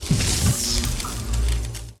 Hit_side.ogg